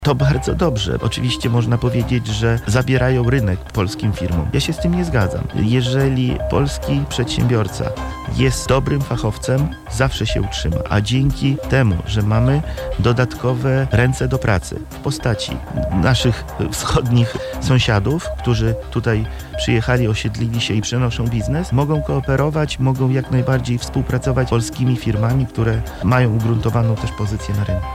[PORANNA ROZMOWA] Nowa fala przedsiębiorczości. Jak Ukraińcy budują biznes w Polsce?
Powstałe nowe firmy tworzą konkurencję dla firm polskich– podkreśla dr Mariusz Filipek Zastępca Rzecznika Małych i Średnich Przedsiębiorców